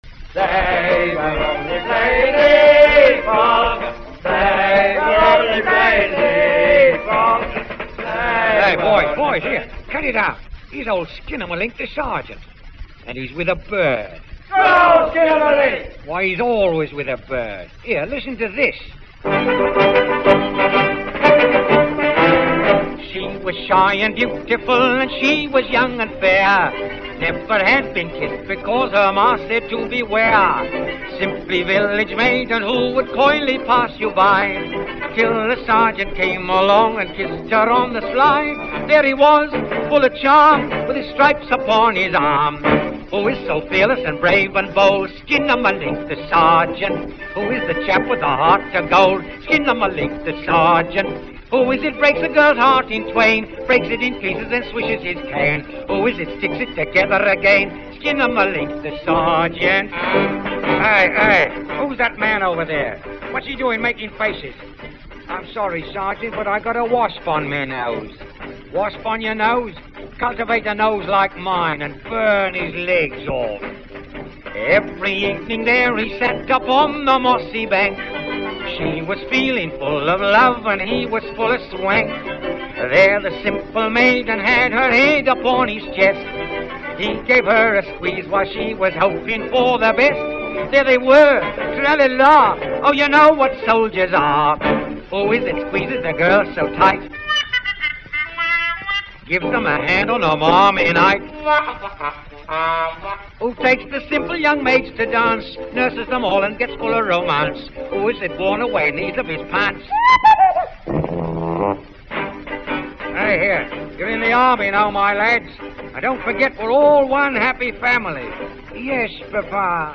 flexible 78 rpm
comic services songs